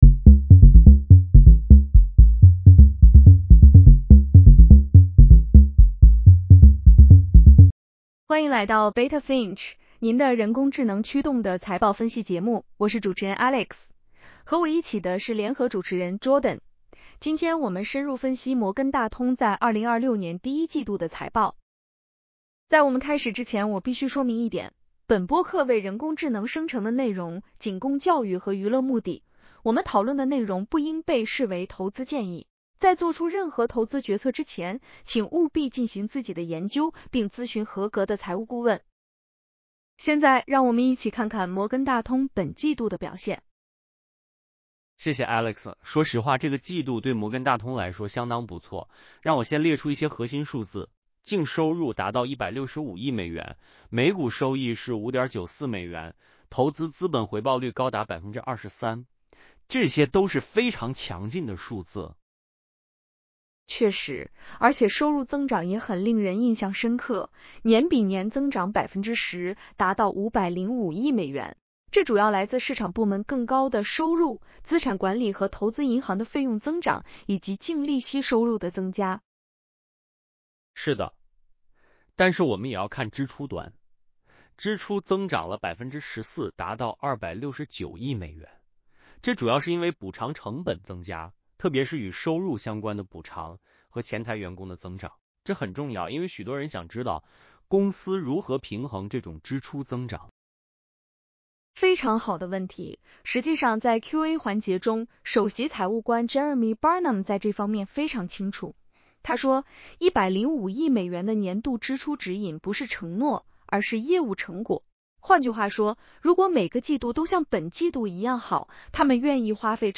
JPMorgan Chase Q1 2026 earnings call breakdown. Full transcript & podcast. 9 min. 5 languages.